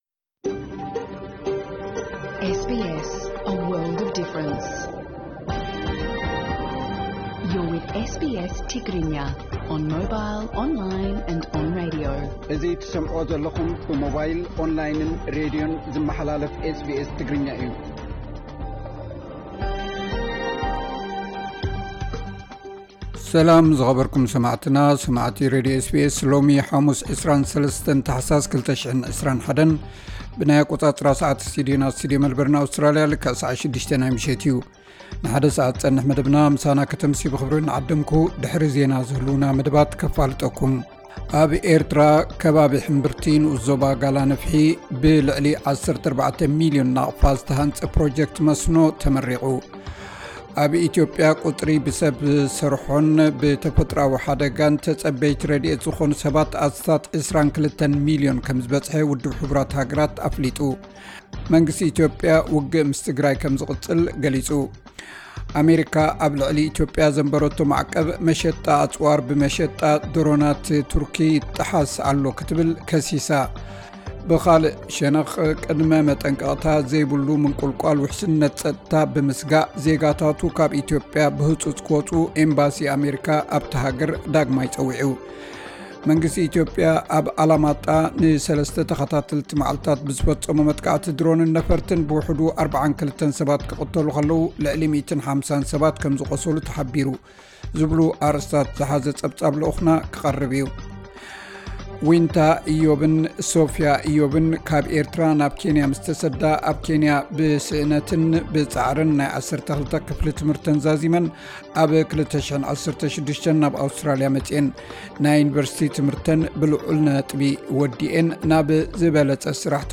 ዕለታዊ ዜና SBS ትግርኛ (23 ታሕሳስ 2021)